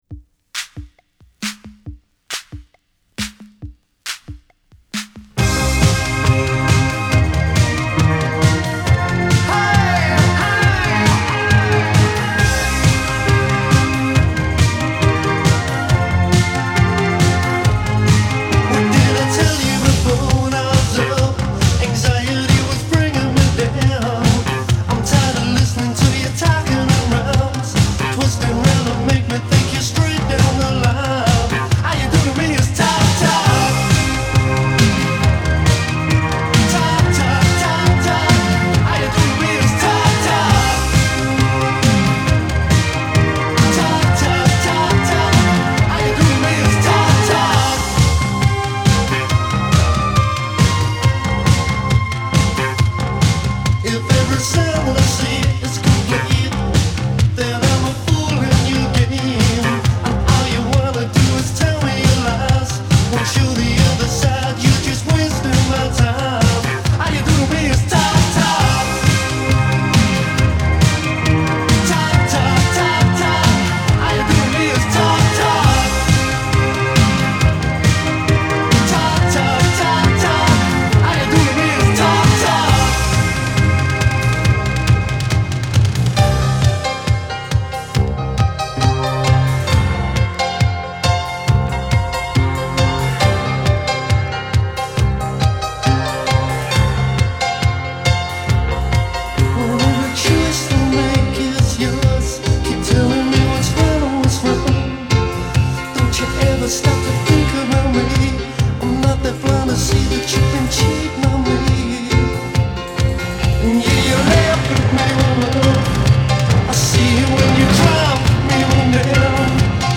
so please enjoy the vinyl rip that I have included below.